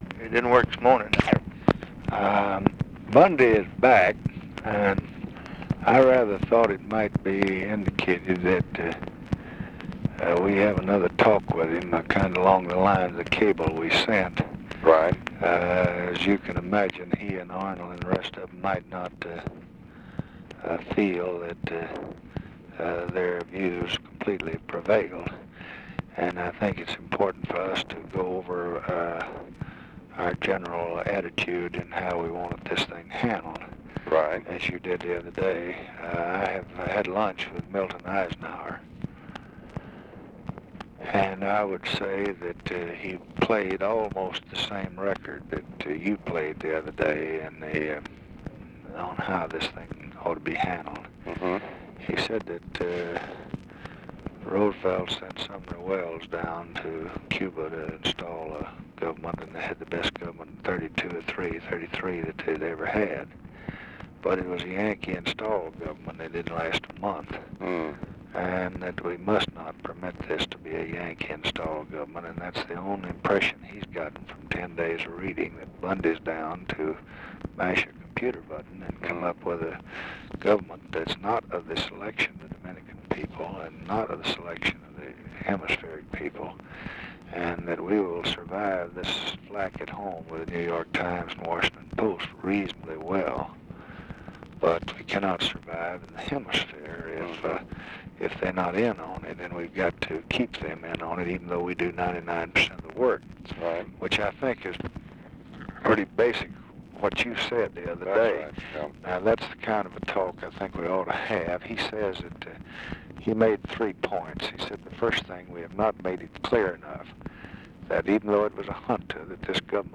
Conversation with DEAN RUSK, May 26, 1965
Secret White House Tapes